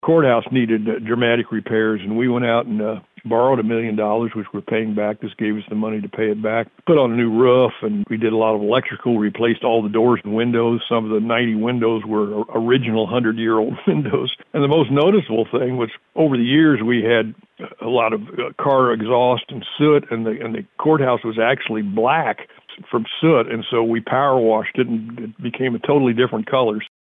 Presiding Commissioner Ed Douglas looked back at the past 11 years, reflecting on changes instituted by the Livingston County commission and the citizens of the county.